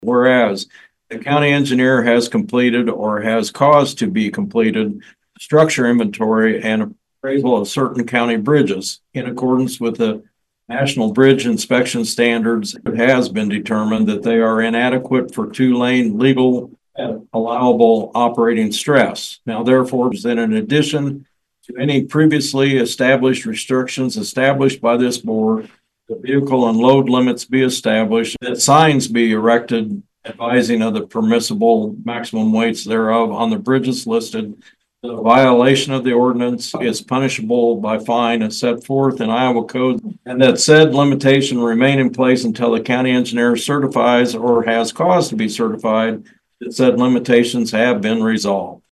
(Red Oak, Iowa) – The Montgomery County Board of Supervisors today (Tuesday), passed a Bridge Embargo Resolution. Supervisor Mark Peterson read the resolution….
Montgomery County BOS meeting, 1-14-25